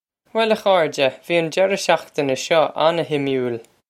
Pronunciation for how to say
Well, ah khore-jeh, vee on jerrah shock-tin-eh shuh on-sim-ool!
This is an approximate phonetic pronunciation of the phrase.